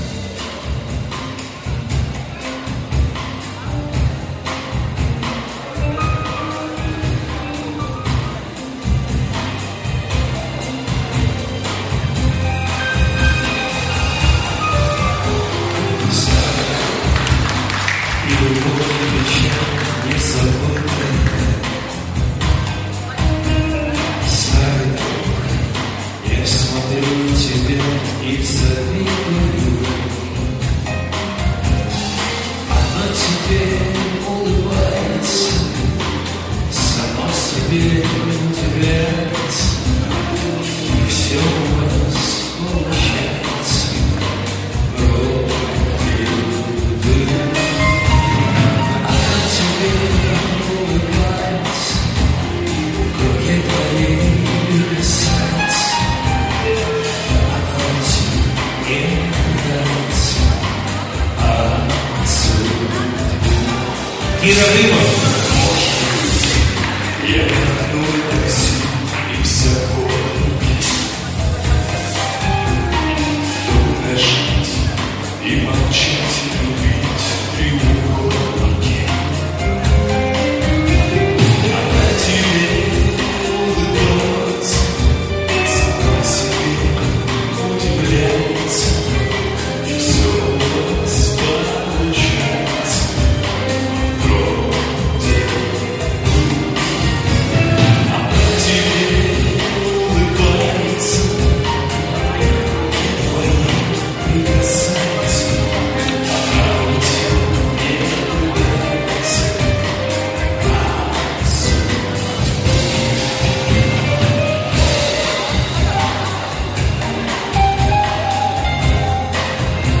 там звук плохой - вам точно нужно ?